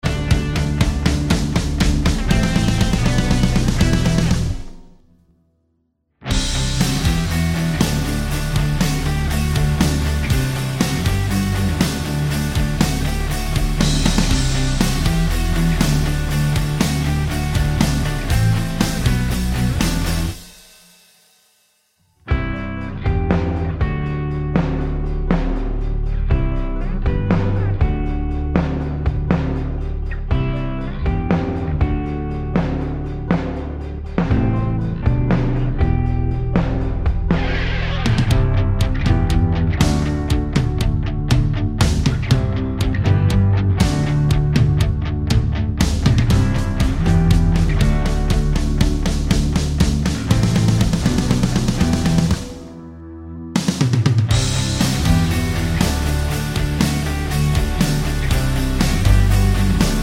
no Backing Vocals R'n'B / Hip Hop 2:50 Buy £1.50